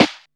• Nice Snare Sound D# Key 107.wav
Royality free acoustic snare sample tuned to the D# note. Loudest frequency: 1520Hz
nice-snare-sound-d-sharp-key-107-cRo.wav